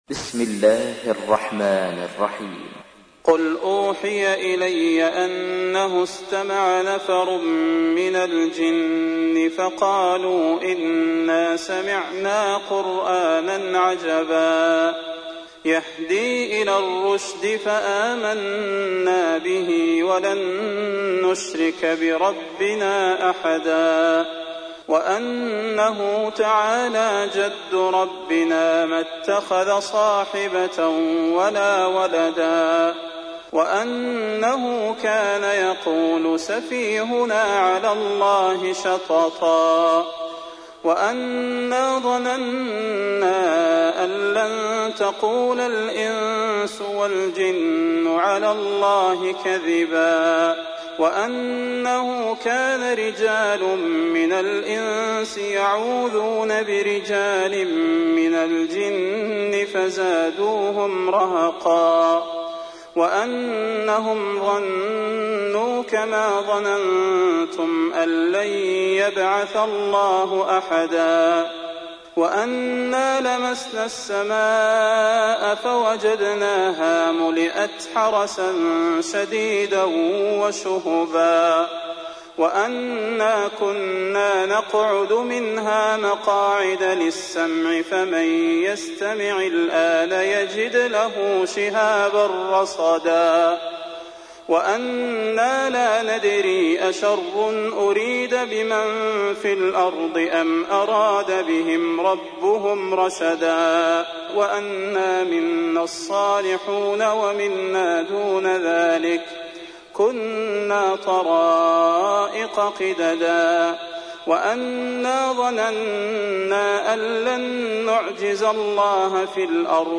تحميل : 72. سورة الجن / القارئ صلاح البدير / القرآن الكريم / موقع يا حسين